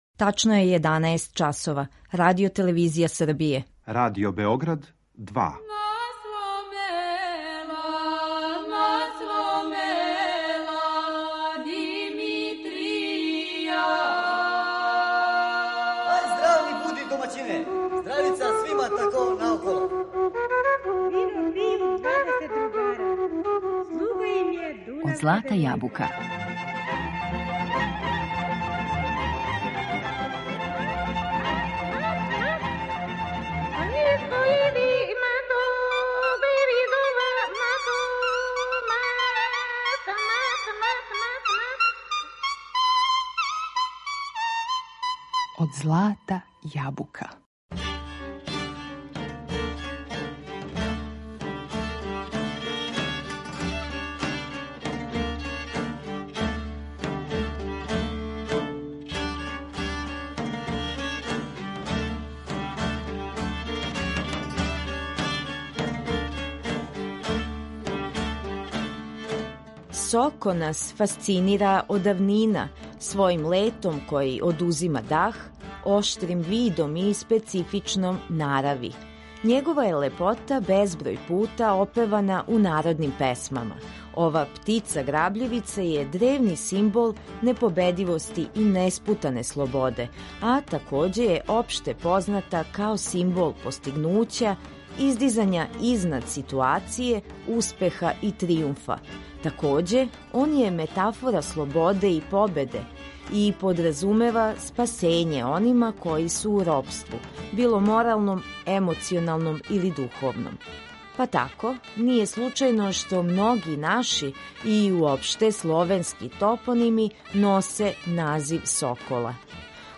У данашњем издању емисије Од злата јабука говоримо о соколу у народним песмама. Слушаћете инструменталне мелодије које изводи ансамбл „Ренесанс", као и традиционалне песме наших најбољих вокалних извођача посвећених изворном звуку.